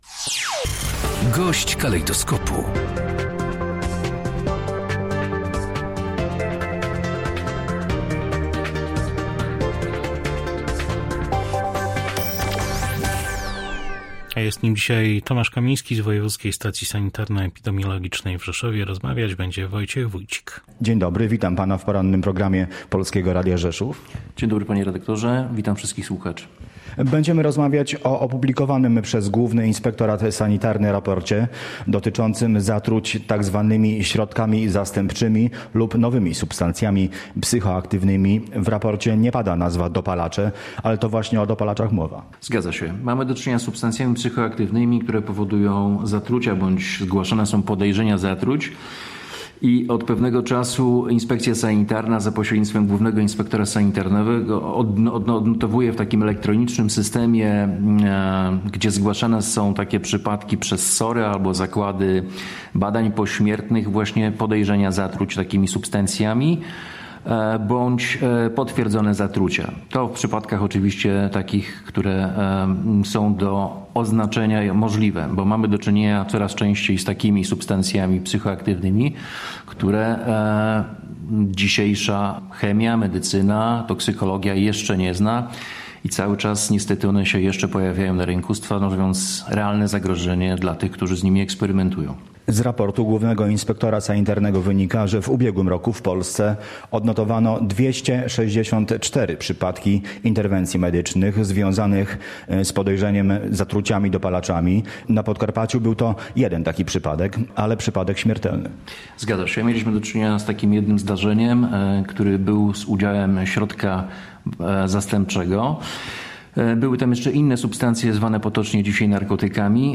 Więcej na ten temat w rozmowie